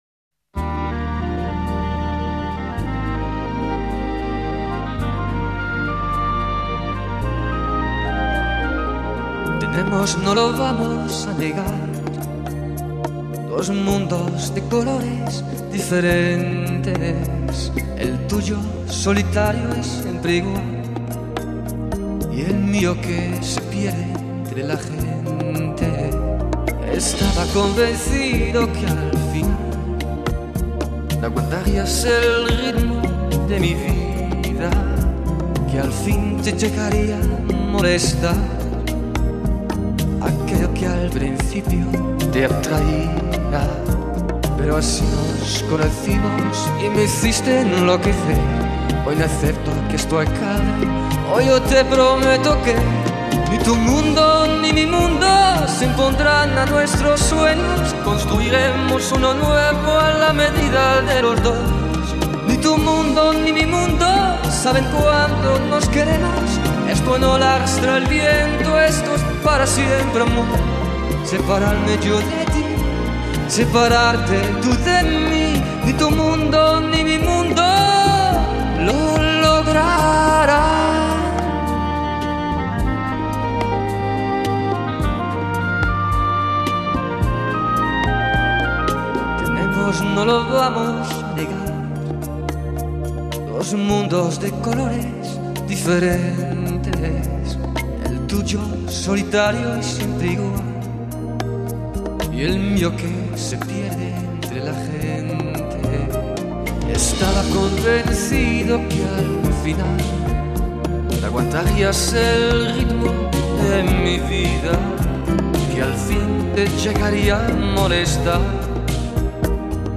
10 Rumba